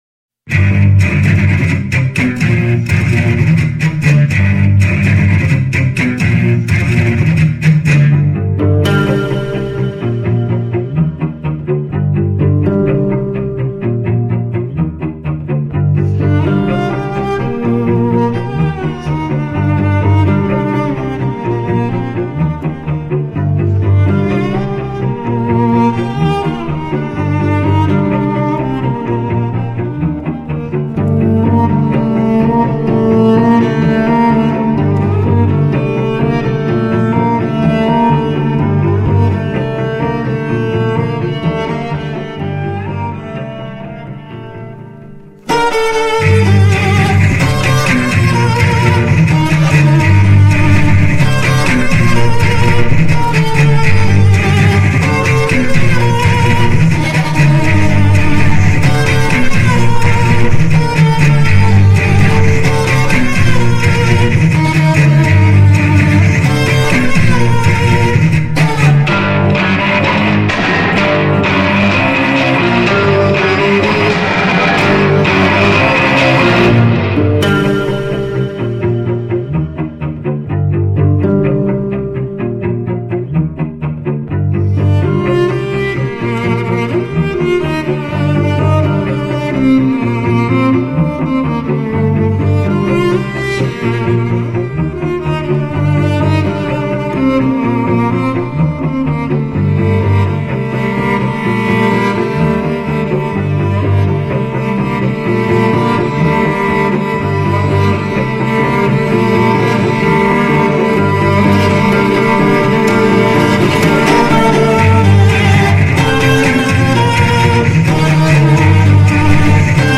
专辑格式：DTS-CD-5.1声道
大胆的无惧表态，跳脱学院派的刻版印象，放纵玩乐一番